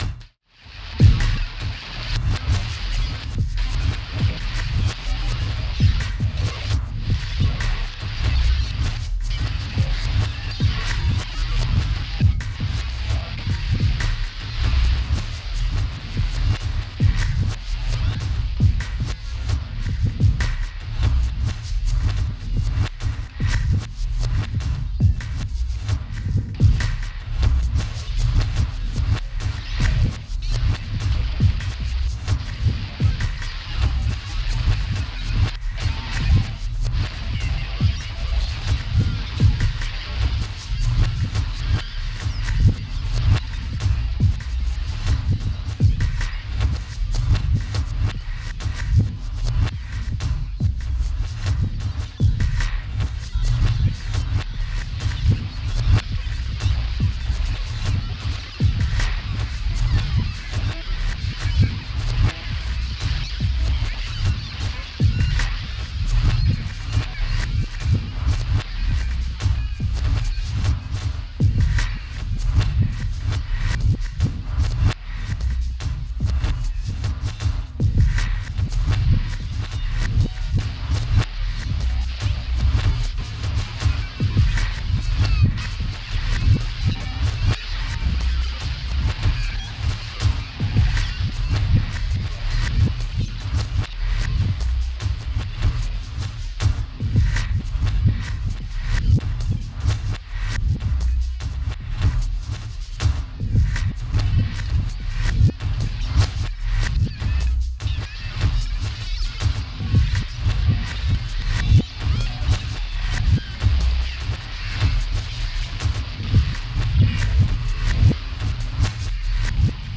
各曲ともに聴き進む内に、独特異様な酩酊感に包まれていくような...。
どうかこの不可思議な律動に身を委ね、あなたの魂を奔放に舞踏させながら、お楽しみください。